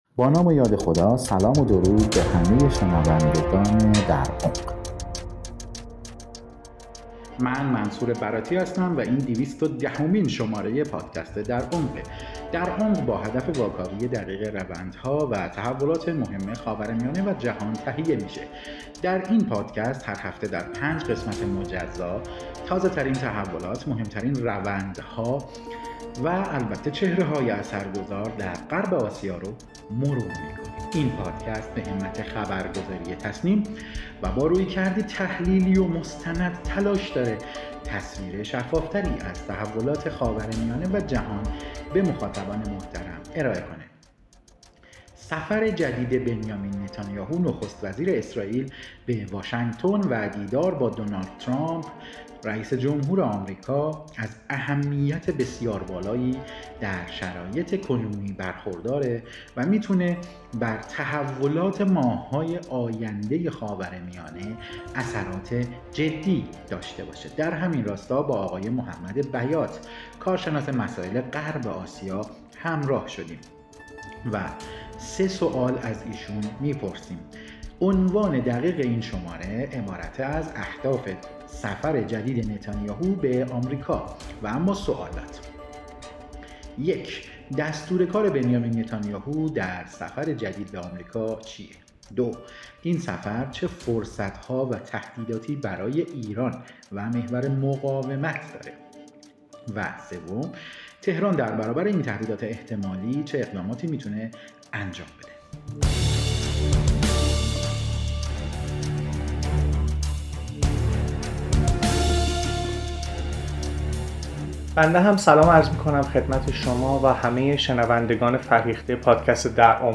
کارشناس مسائل غرب آسیا سفر اخیر بنیامین نتانیاهو به واشنگتن را بررسی می‌کند.